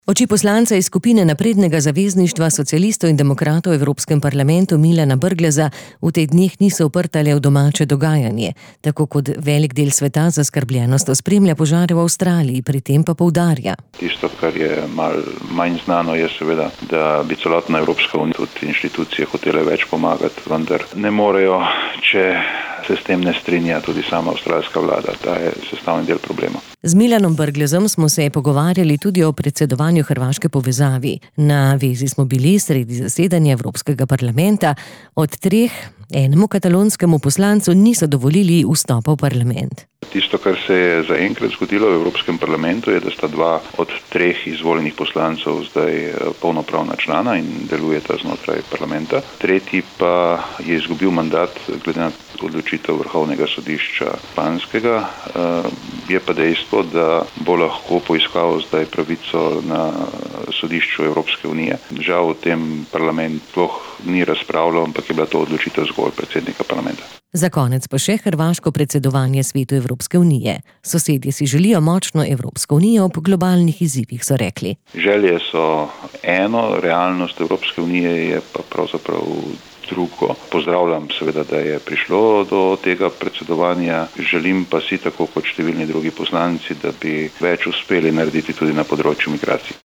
Tanja Fajon je za Radio 94 povedala: